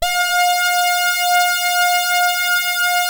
77-SAWRESWET.wav